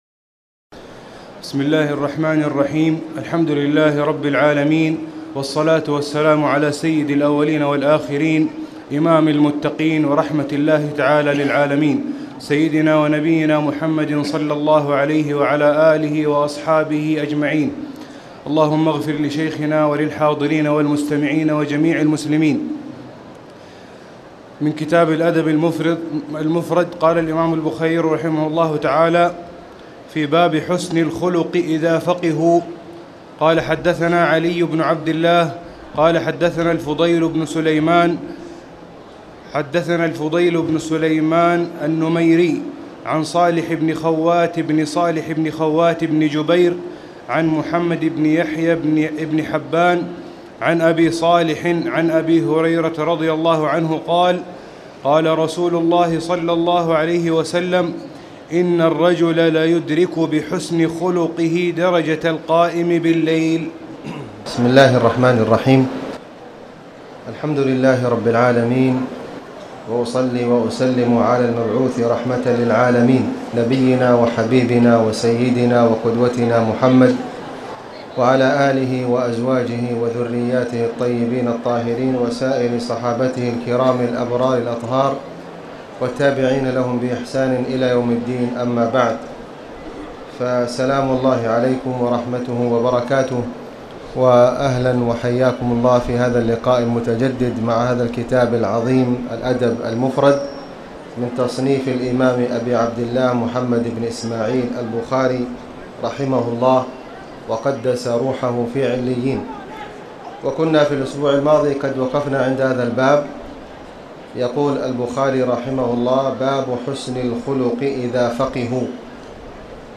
تاريخ النشر ٢٧ رجب ١٤٣٨ هـ المكان: المسجد الحرام الشيخ: خالد بن علي الغامدي خالد بن علي الغامدي باب حسن الخلق اذا فقهوا